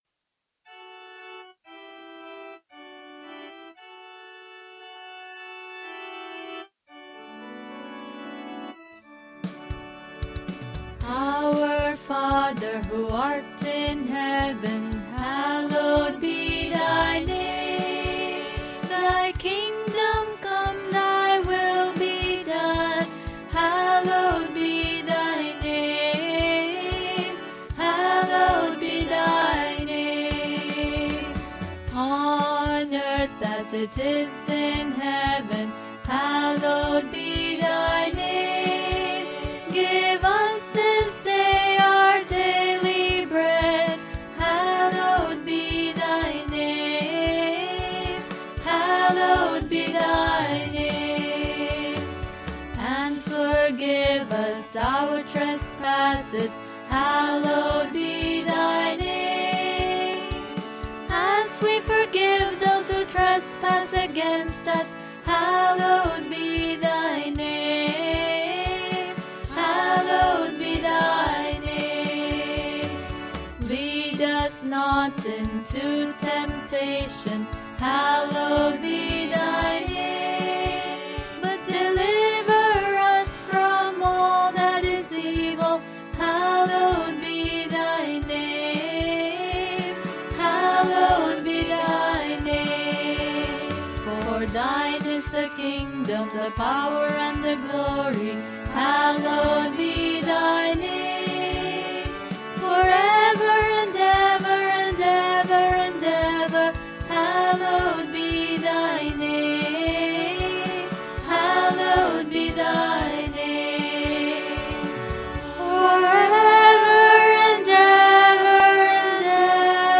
1. Devotional Songs
Major (Shankarabharanam / Bilawal)
8 Beat / Keherwa / Adi
Medium Fast
5 Pancham / G
2 Pancham / D